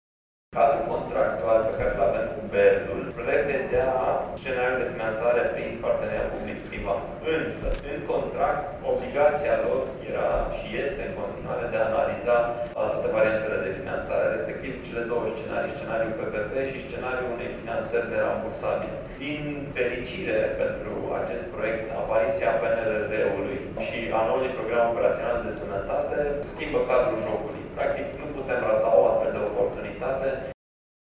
Anunțul a fost făcut de primarul municipiului Brașov, Allen Coliban: